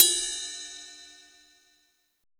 POP RIDEBEL.wav